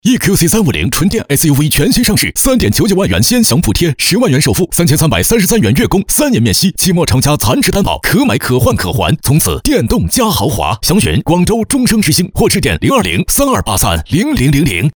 男13号